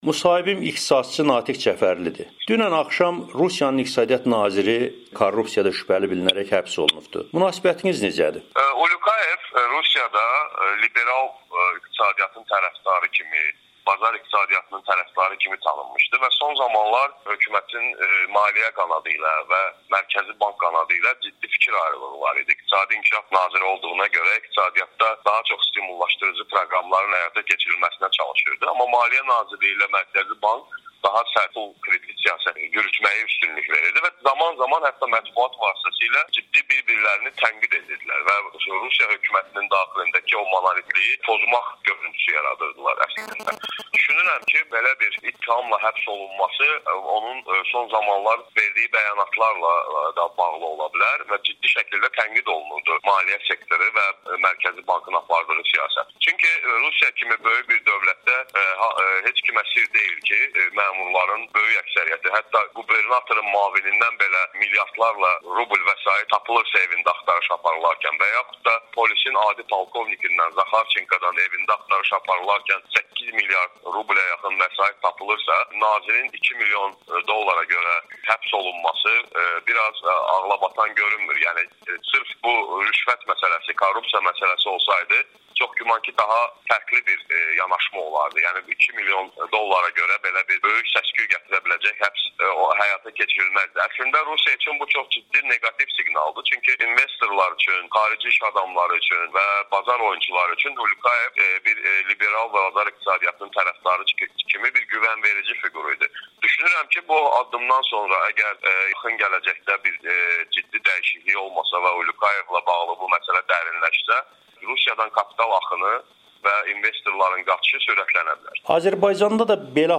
Müsahibələri təqdim edirik: